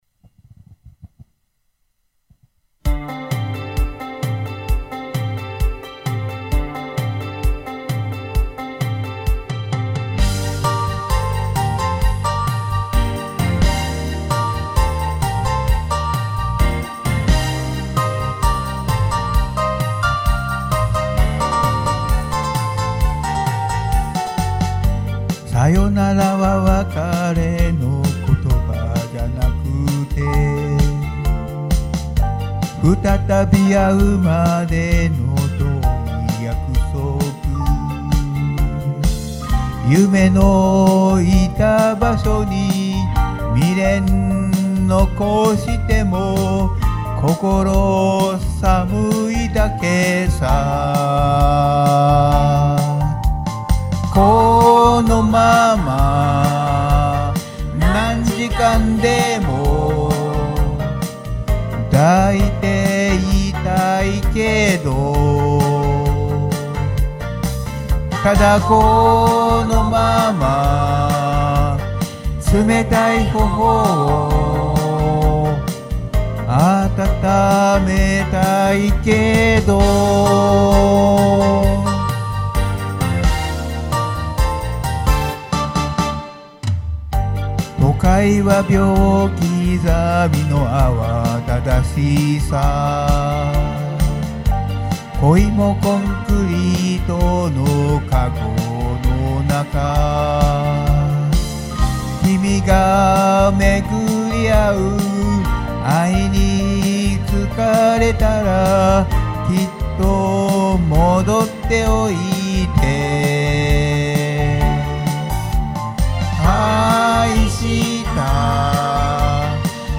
録音方法： オンマイク／カラオケ用マイクロフォン使用／エコーなし